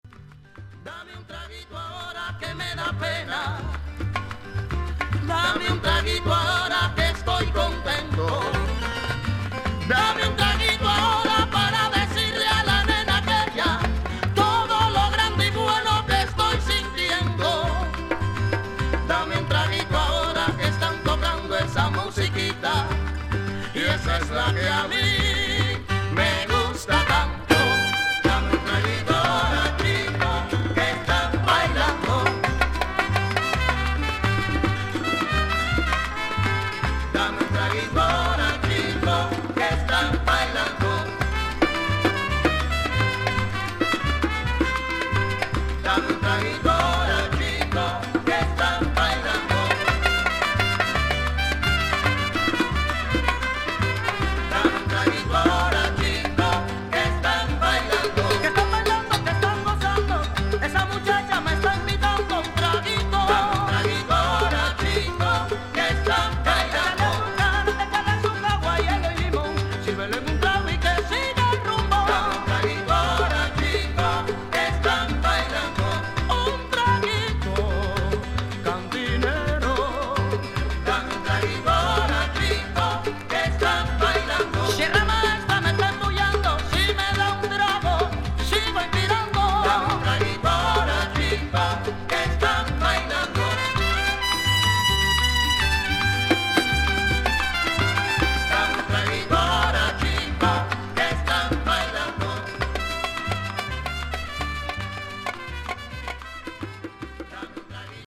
1976年に結成されたキューバのグループ
彼らはキューバ東部の山脈で生まれた1920年代の伝統的なソンの